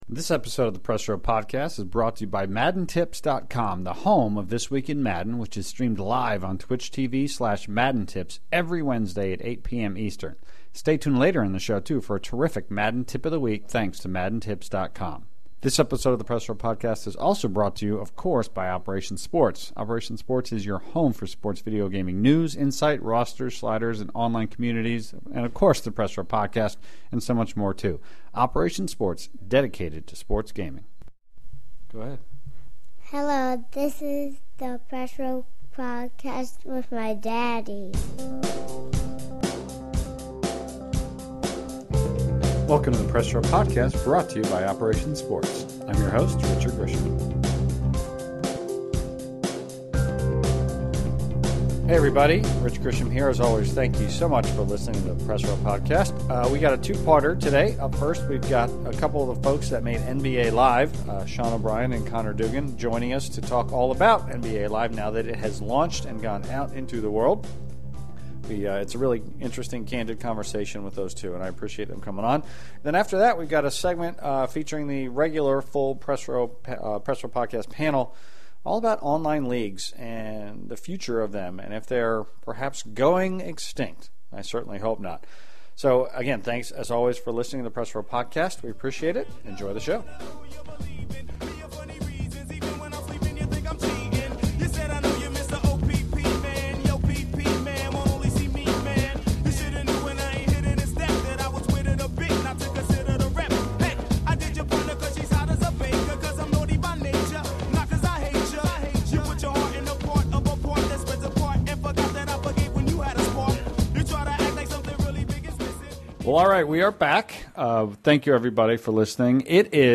It’s a wide-ranging conversation that looks at the just-released Live as well as the future, which is looking better today for the franchise than at any point in the past four years. The second part of the show brings all the regular Press Row panelists together to look more into a concerning trend in modern sports games – the phasing out of online leagues in favor of other modes.